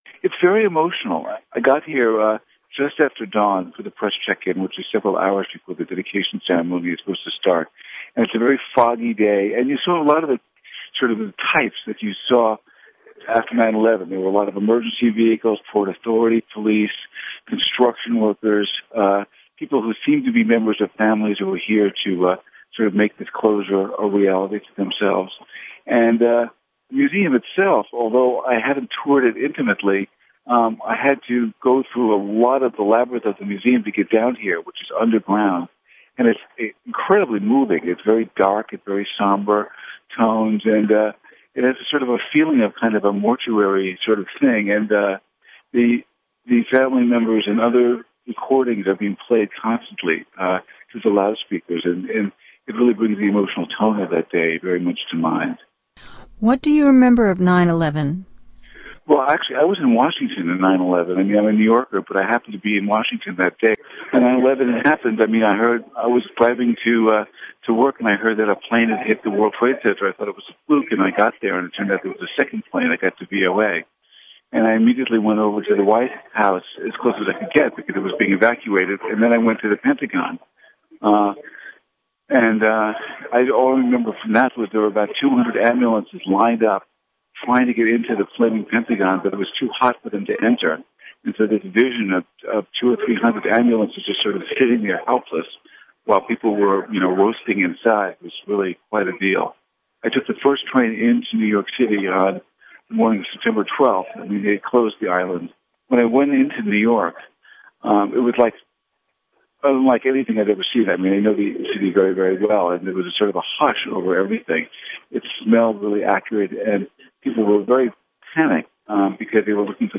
NY Reporter